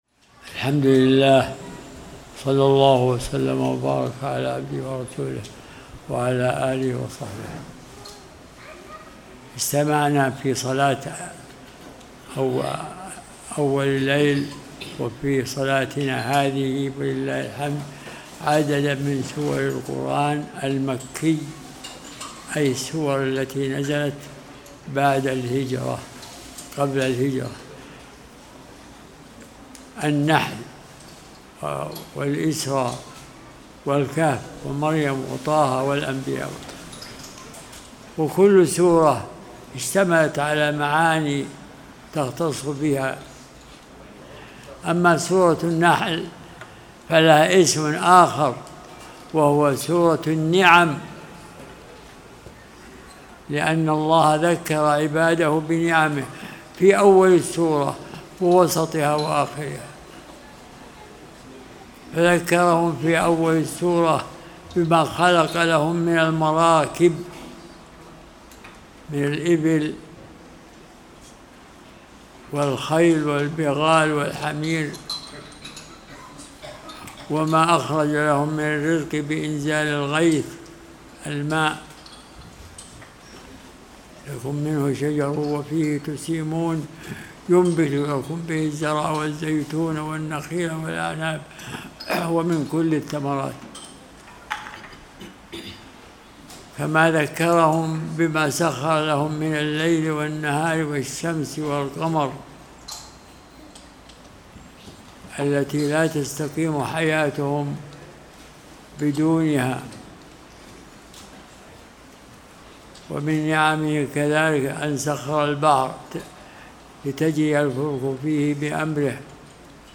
(47) فوائد مستنبطة من سور القرآن - سورة النحل والإسراء (استراحة صلاة القيام في رمضان 1447)